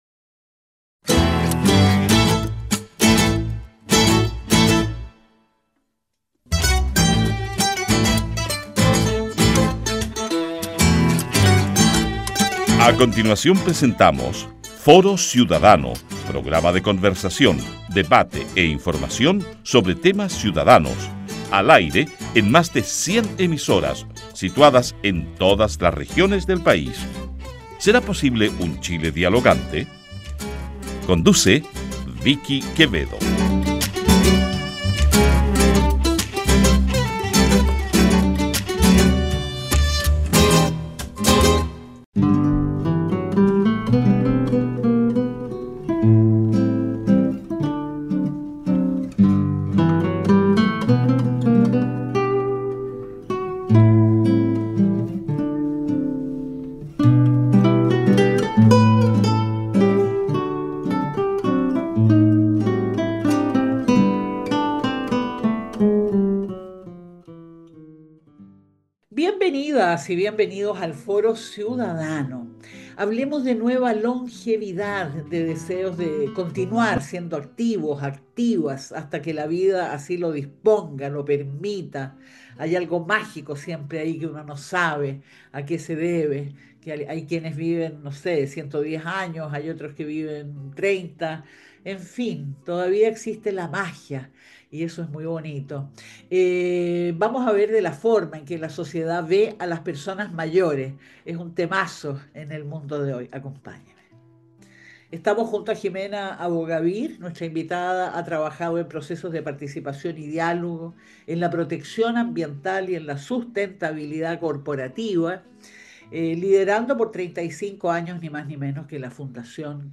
Conversamos sobre los deseos de continuar siendo activas y activos hasta que la vida así lo permita, de la forma en que la sociedad ve a las personas mayores.